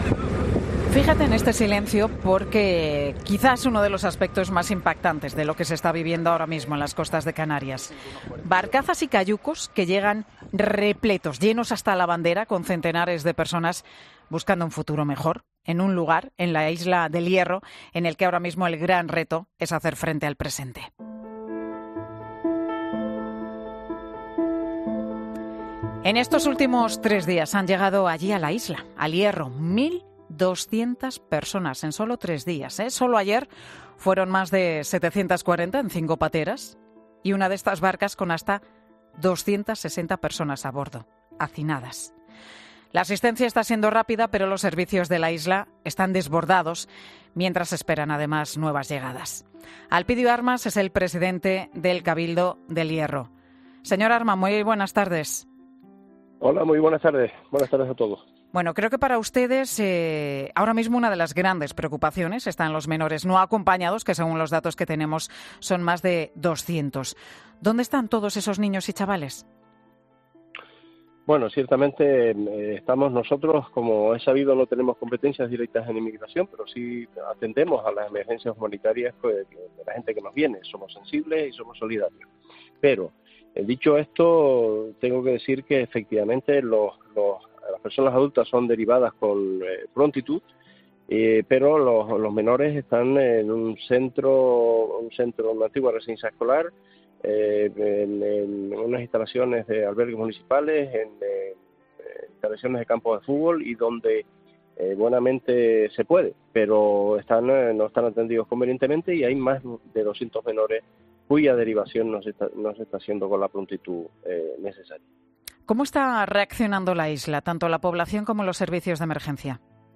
El presidente del Cabildo de El Hierro reconoce en Mediodía COPE que la llegada masiva de inmigrantes a sus costas está poniendo a la isla "en un aprieto muy gordo"
Alpidio Armas presidente del Cabildo de El Hierro, n Mediodía COPE